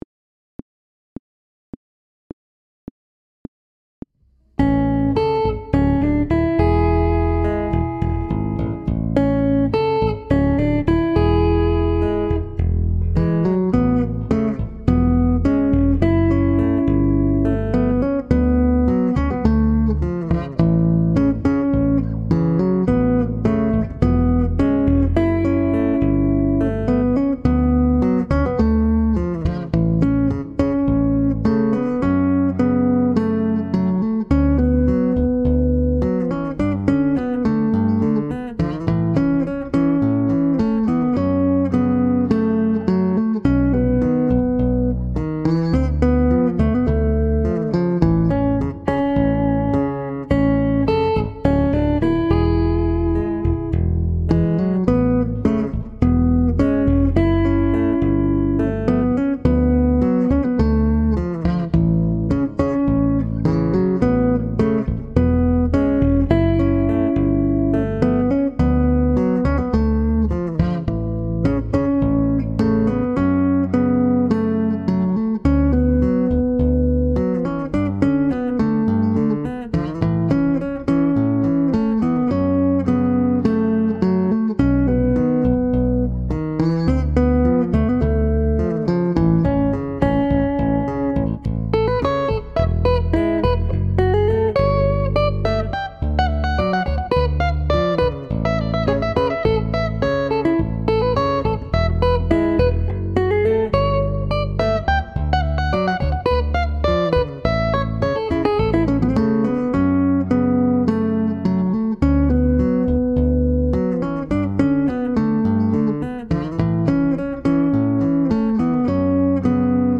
• Instrumental
The part is quite fast (sixteenth notes at 104 bpm).